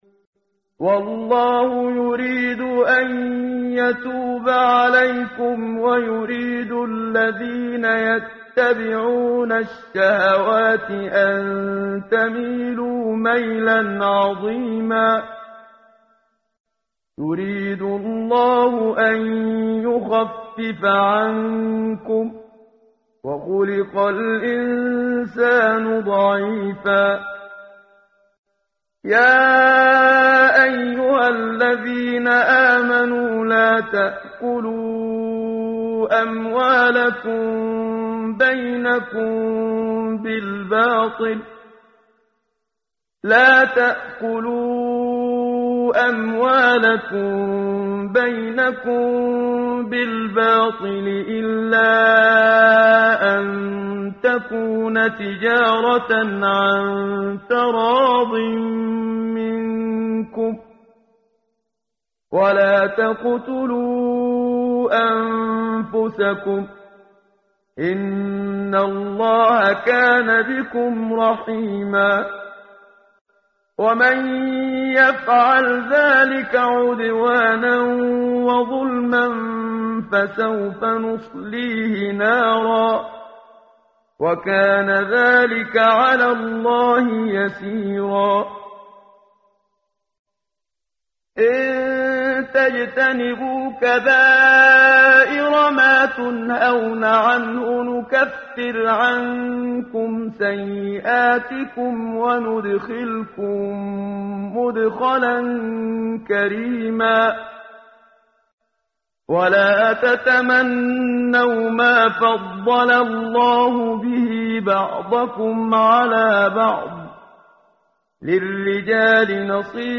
ترتیل
ترتیل صفحه 83 سوره مبارکه سوره نساء (جزء پنجم) از سری مجموعه صفحه ای از نور با صدای استاد محمد صدیق منشاوی
quran-menshavi-p083.mp3